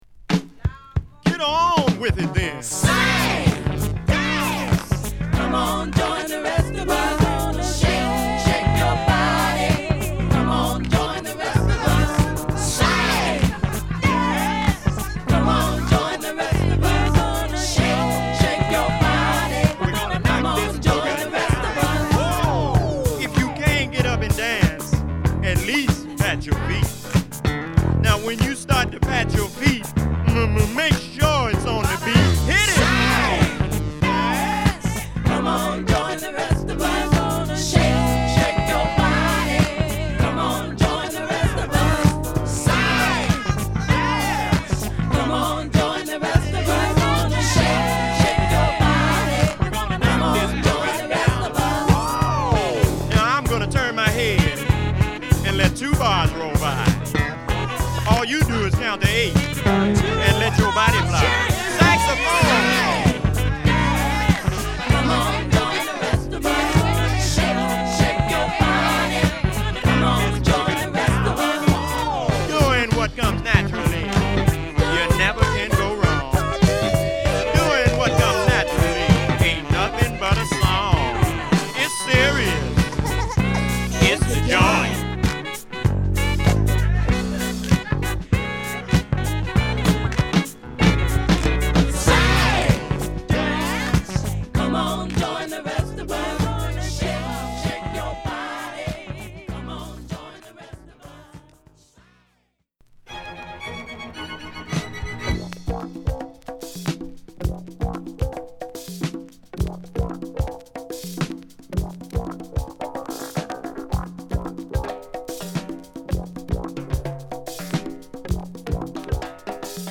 メリーランドはバルチモア出身のサックス奏者
ロマンチックなアルトサックスが響くフュージョンファンク